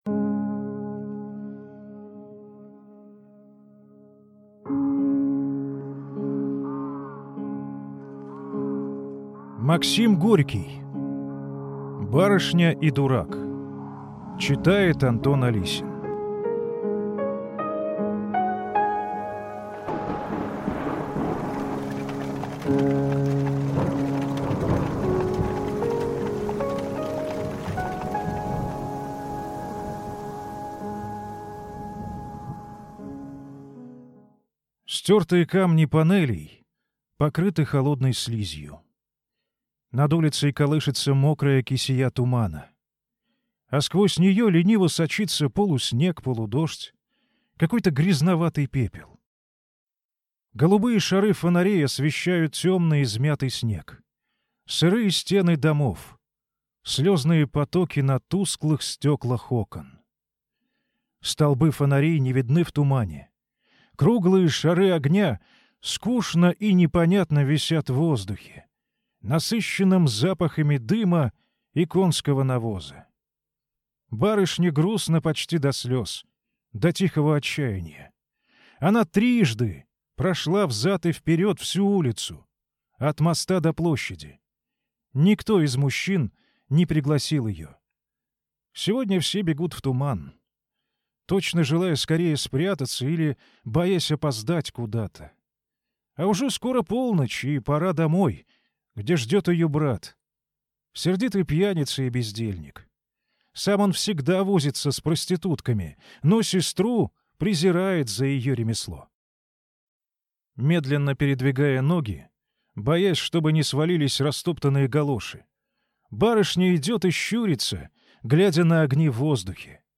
Аудиокнига Барышня и дурак | Библиотека аудиокниг